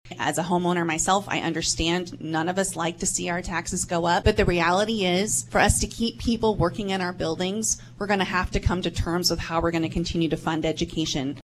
Rising property taxes was a point of emphasis at the USD 383 candidate forum, hosted by the League of Women Voters Saturday.